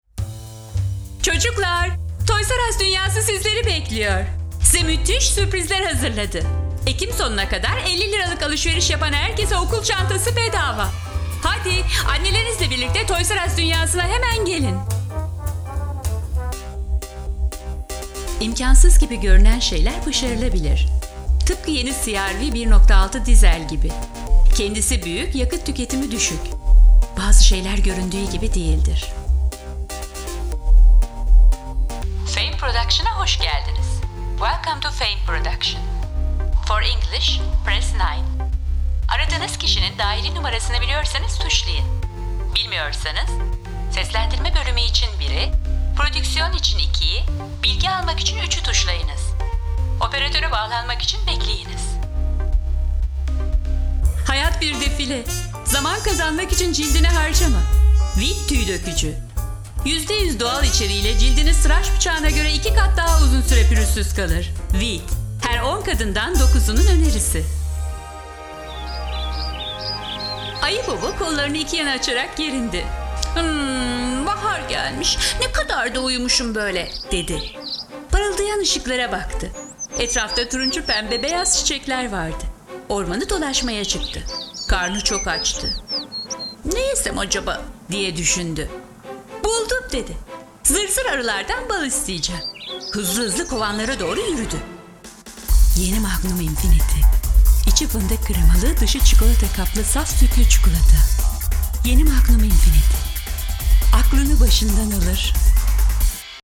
Sprechprobe: Werbung (Muttersprache):
Smooth, Natural, Sexy, Soft / Gentle, Sophisticated, Warm, YoungAngry, Announcer, Artistic, Charismatic, Cold, Concerned, Deep, Energetic, Friendly, Fun, Glamorous, Happy, Luxurious